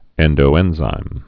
(ĕndō-ĕnzīm)